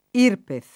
vai all'elenco alfabetico delle voci ingrandisci il carattere 100% rimpicciolisci il carattere stampa invia tramite posta elettronica codividi su Facebook IRPEF [ & rpef ] s. f. — sigla di Imposta sul Reddito delle Persone Fisiche